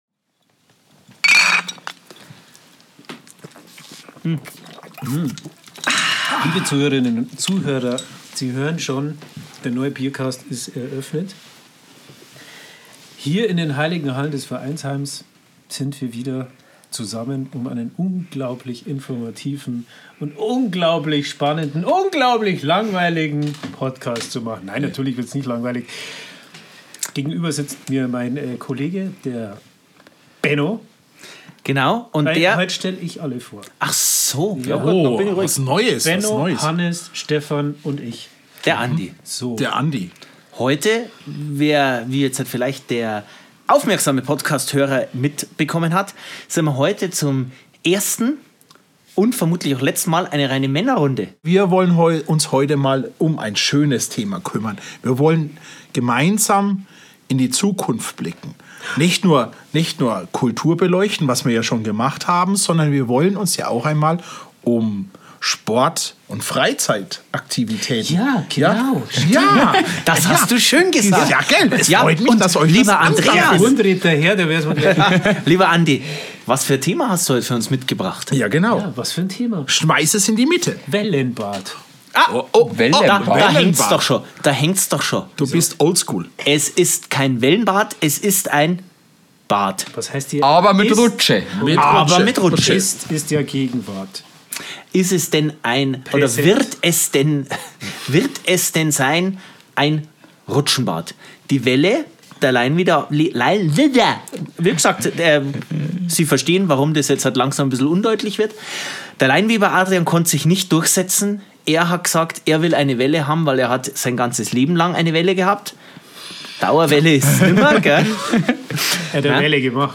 So einfach und locker, wie dies im Ergebnis zu hören war, war es nicht.